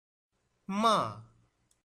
falling.mp3